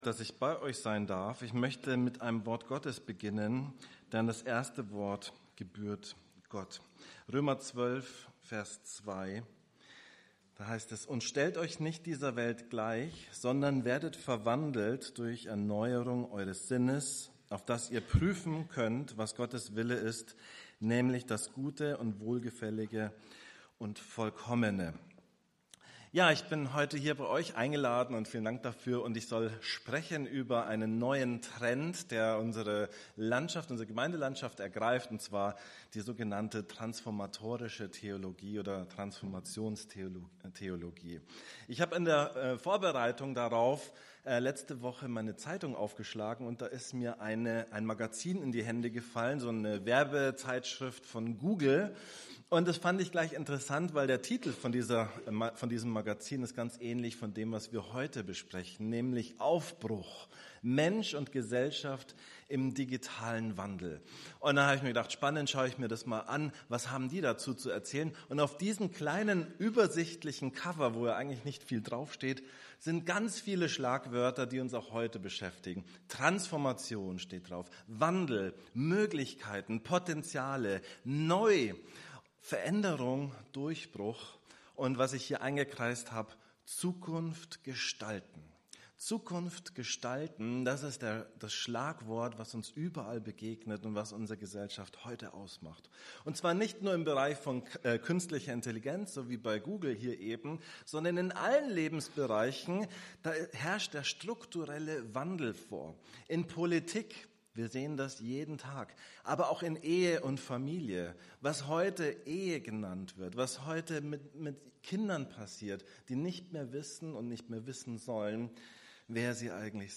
Vortrag: Glauben in einer sich wandelnden Welt | Christliche Gemeinde München-Sendling
15. Reher Bibelbund-Konferenz27. Oktober 2024